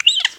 squeak.wav